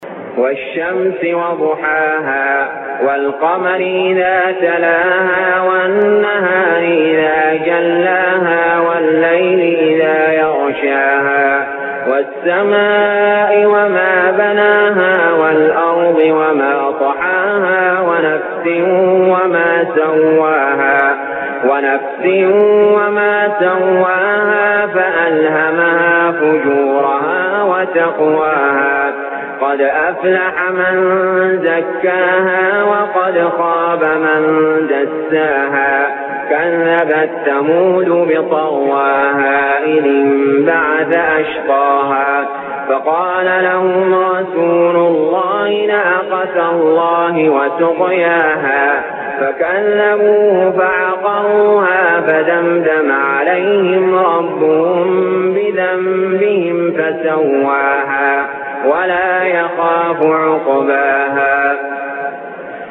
المكان: المسجد الحرام الشيخ: علي جابر رحمه الله علي جابر رحمه الله الشمس